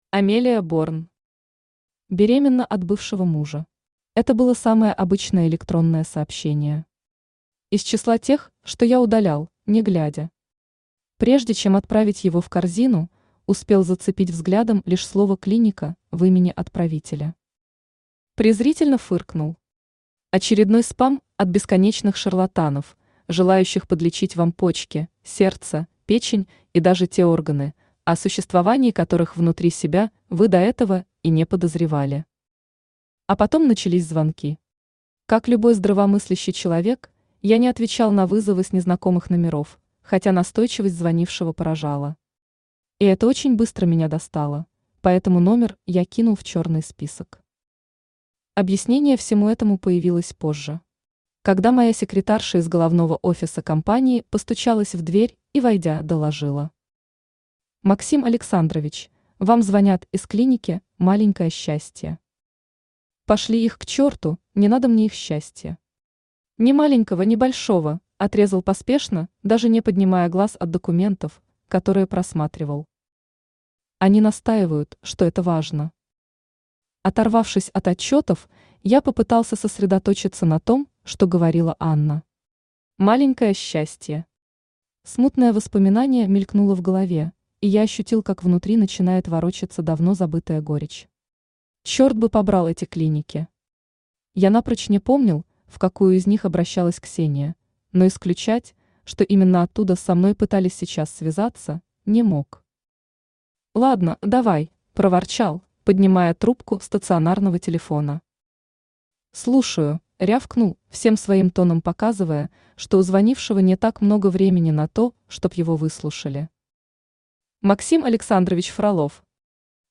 Аудиокнига Беременна от бывшего мужа | Библиотека аудиокниг
Aудиокнига Беременна от бывшего мужа Автор Амелия Борн Читает аудиокнигу Авточтец ЛитРес.